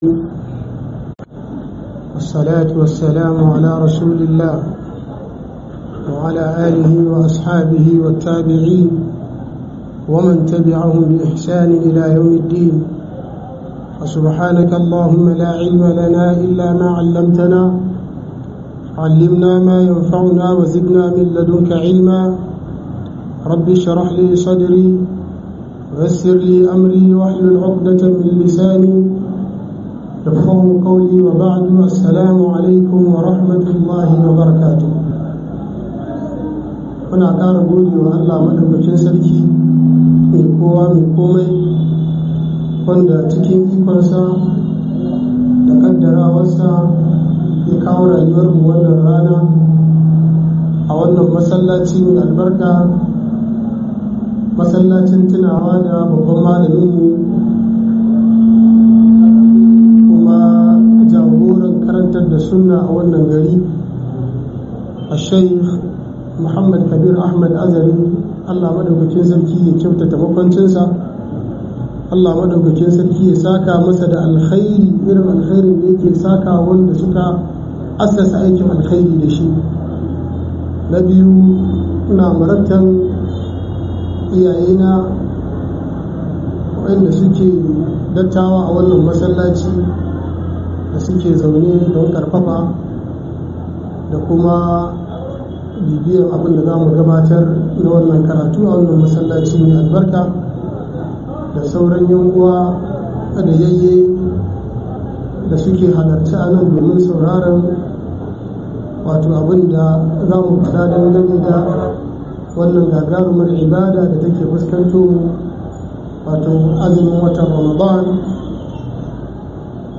Play Radio